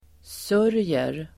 Uttal: [s'ör:jer]